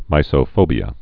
(mīsō-fōbē-ə)